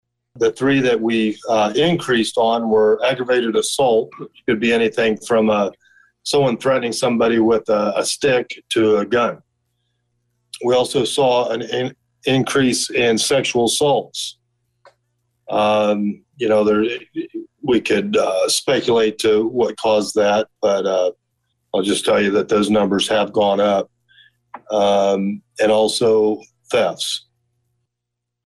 So says Danville Police Chief Christopher Yates who issued a report Tuesday evening to the Public Services Committee of the Danville City Council….